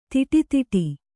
♪ tiṭi tiṭi